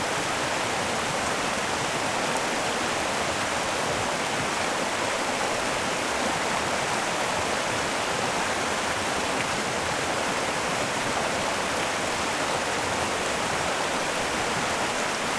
River Cascade large.wav